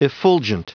Prononciation du mot effulgent en anglais (fichier audio)